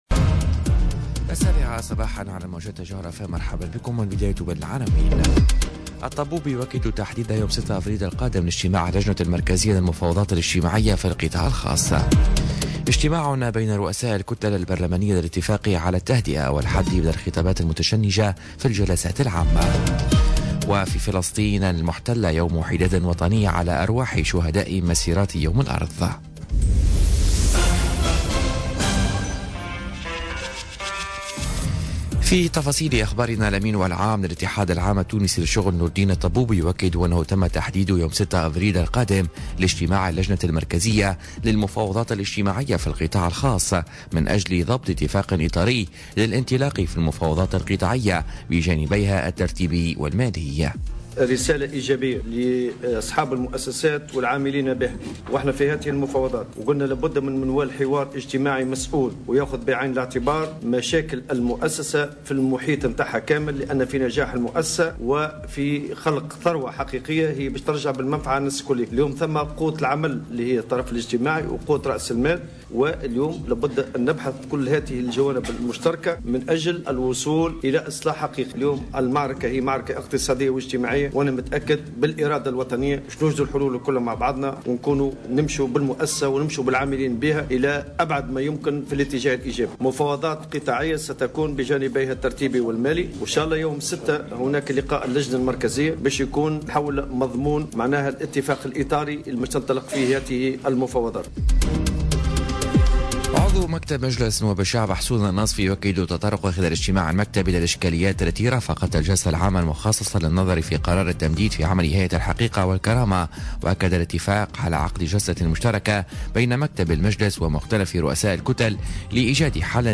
نشرة أخبار السابعة صباحا ليوم السبت 31 مارس 2018